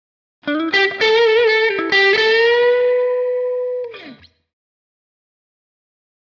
Electric Guitar
Blues Guitar - Short Solo 2